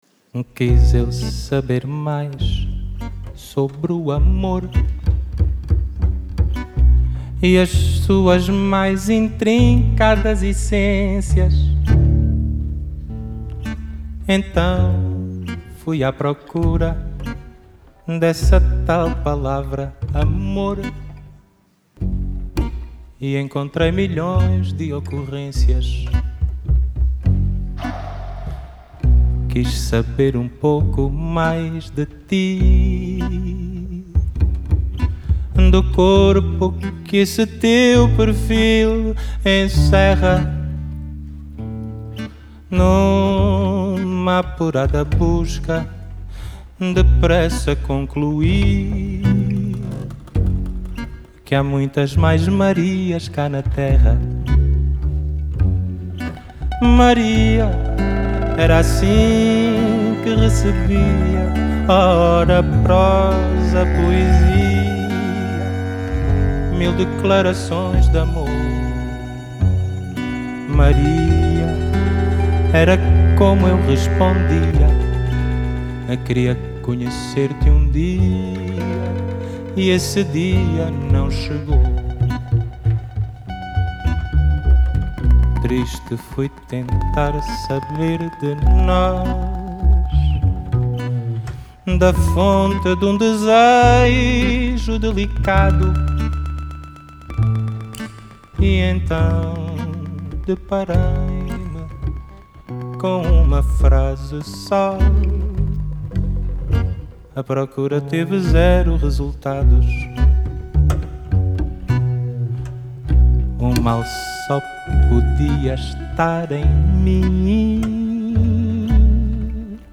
Genre: Fado, Fado Bossa Nova, Folk, World